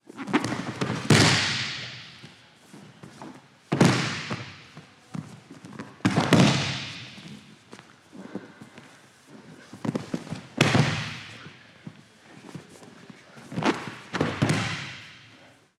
Varias caídas por golpes de judo 2